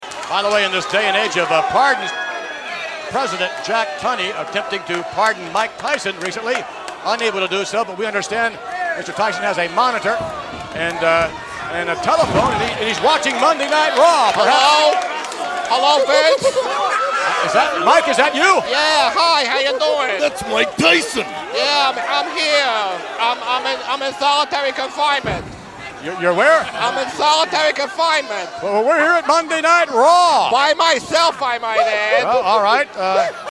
a Mike Tyson impersonation that makes Blade Braxton’s sound stellar in comparison and him telling us about the WWF version of the Amy Fisher Story, with Vince McMahon starring as Joey Buttafuco.
miketyson.mp3